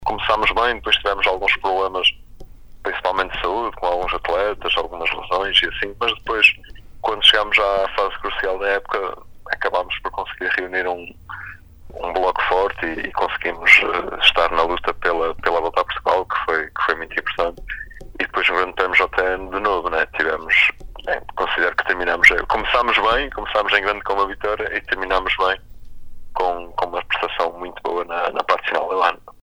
Em entrevista à Sintonia